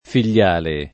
figliale [ fil’l’ # le ]